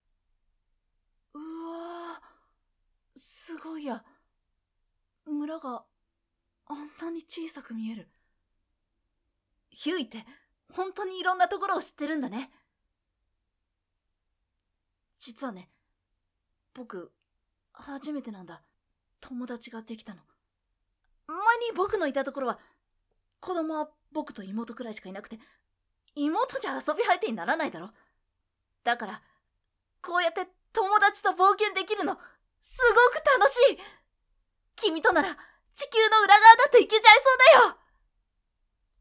V O I C E
少年